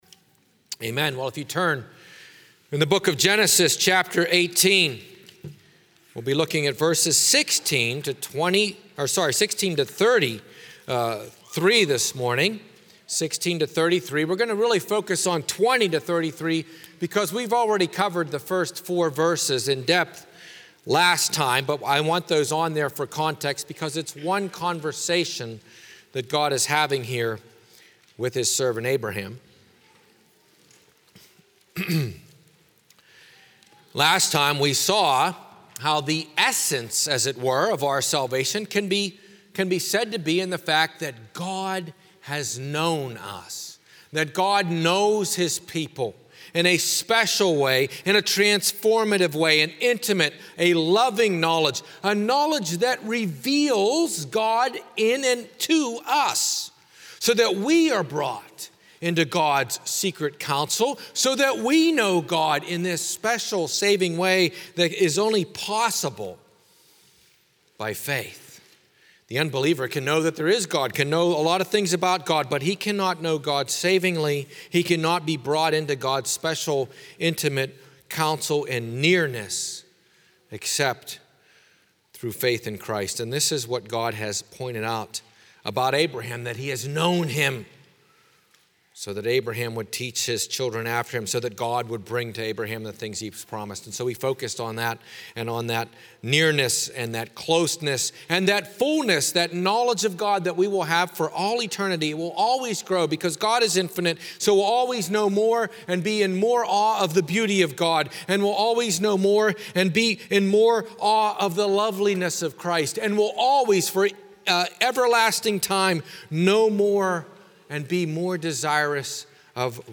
00:00 Download Copy link Sermon Text Believing in Jesus Christ as the eternal Son of God